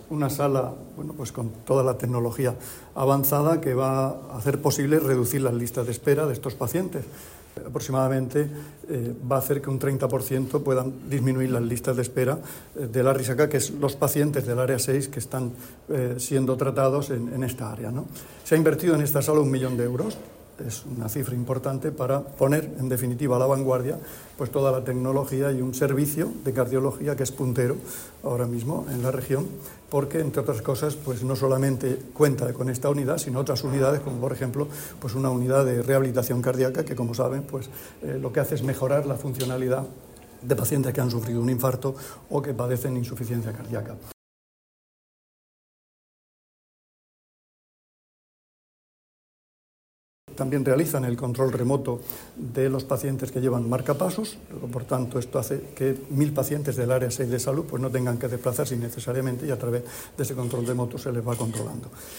Declaraciones del consejero de Salud, Juan José Pedreño, sobre la nueva Unidad de Electrofisiología del hospital Morales Meseguer.